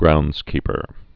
(groundzkēpər) also ground·keep·er (ground-)